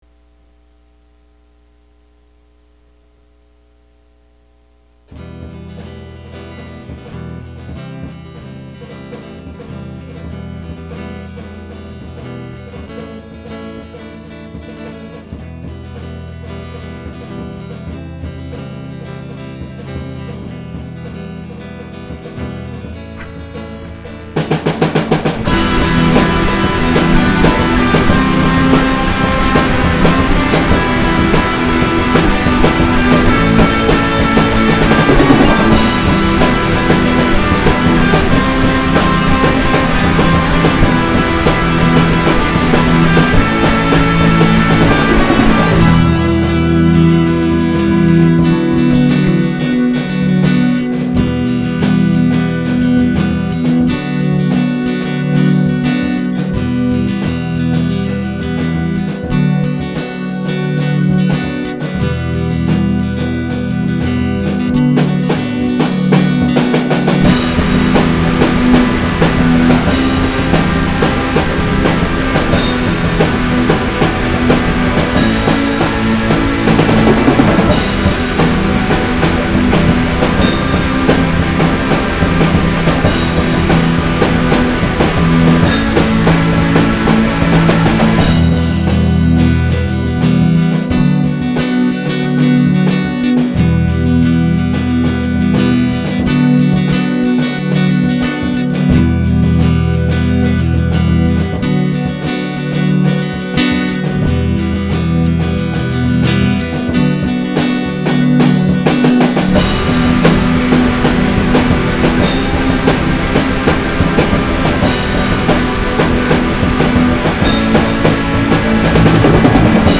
Ballad
Status: Instrumental completed; no lyrics/vocals